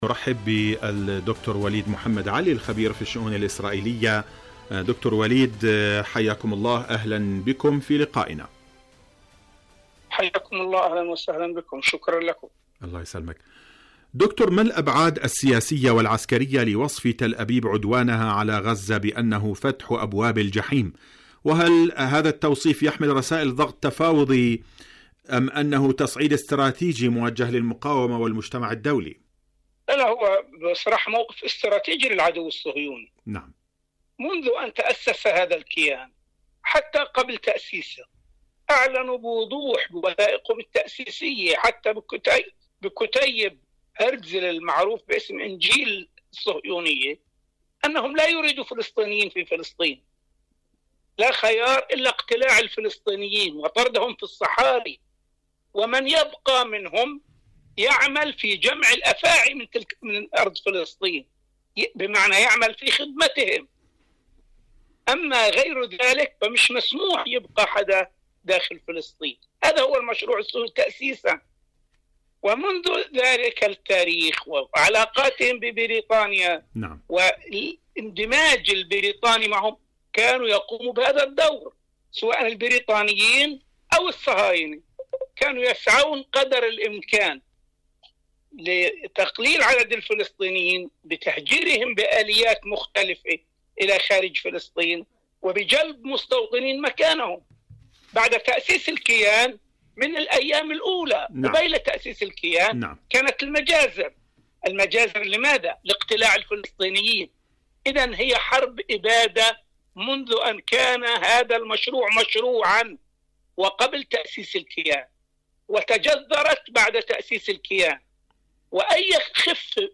برنامج فلسطين اليوم مقابلات إذاعية